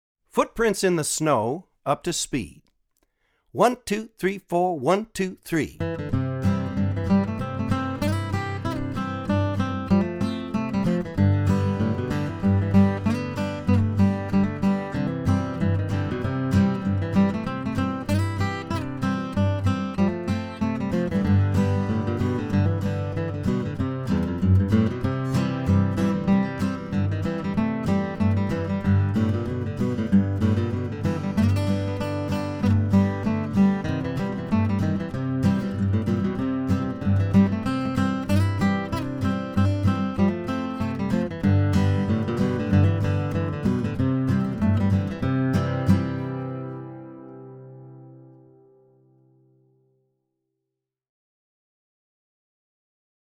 DIGITAL SHEET MUSIC - FLATPICK GUITAR SOLO
Online Audio (both slow and regular speed)